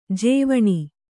♪ jēvaṇi